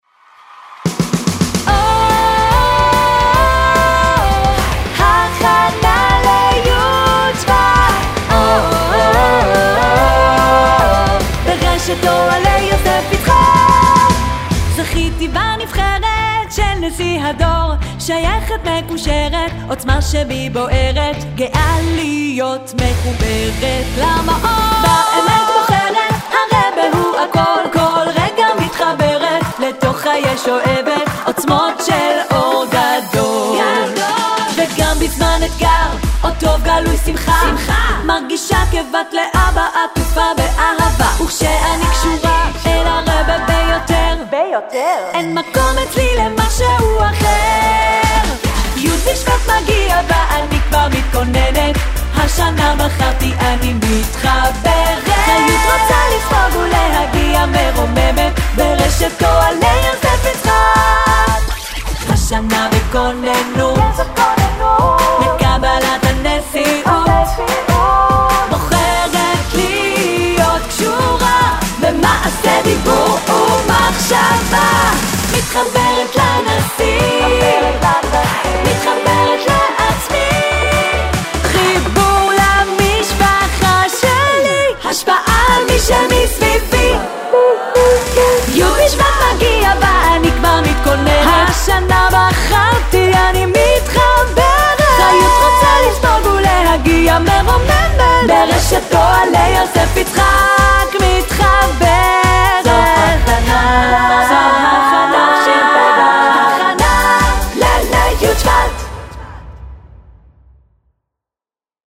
ג'ינגל ימי הכנה - מתחברת
גינגל-רוצעת-שמע.mp3